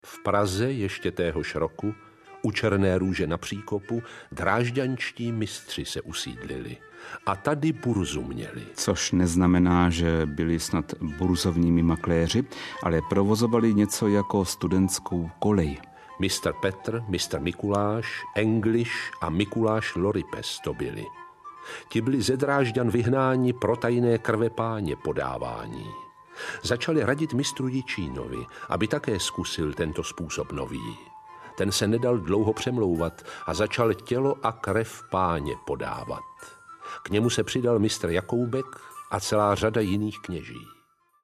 Vyberte Audiokniha 699 Kč Další informace